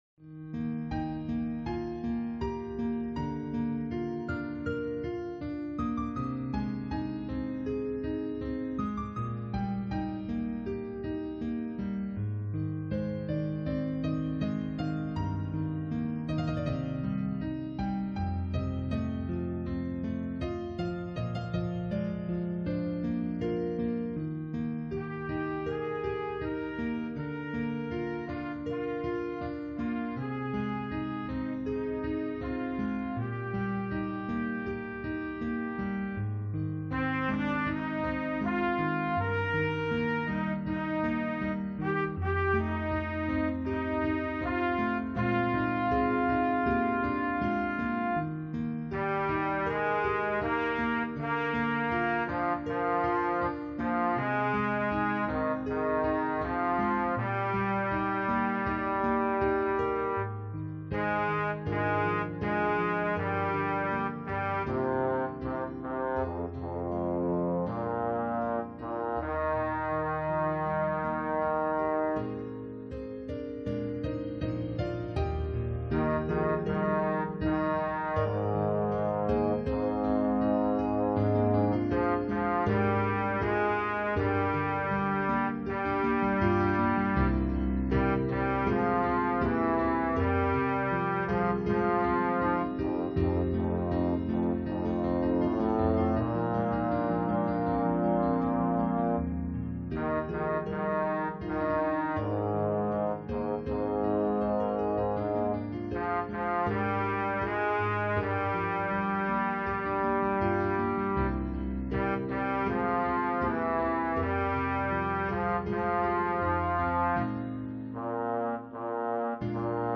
장로성가단 연습음원